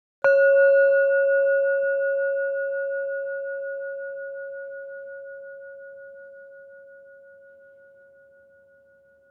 bell bowl gong metal metallic percussion ring sound effect free sound royalty free Sound Effects